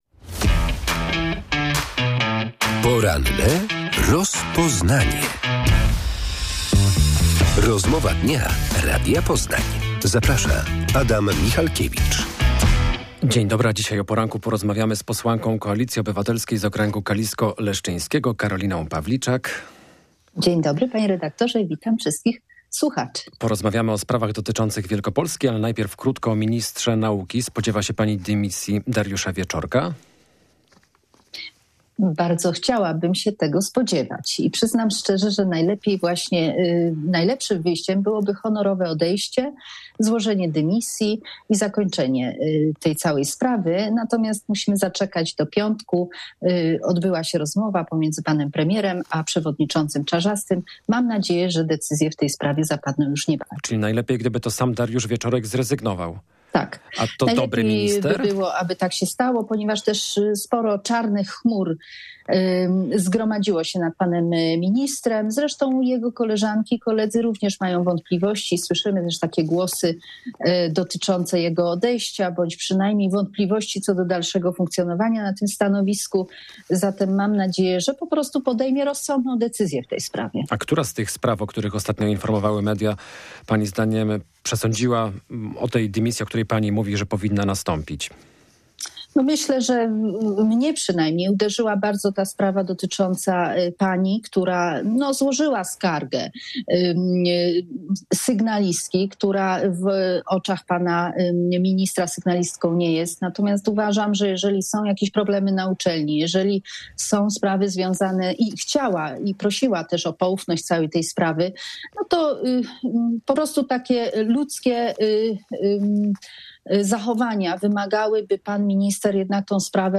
Czy jest szansa na budowę nowej drogi ekspresowej? Gościem Radia Poznań jest posłanka Karolina Pawliczak z Koalicji Obywatelskiej.